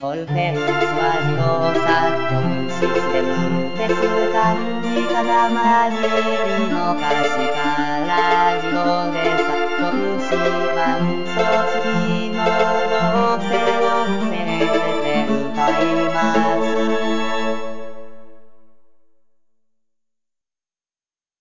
自動で作曲し、伴奏つきの
合成音声で歌います。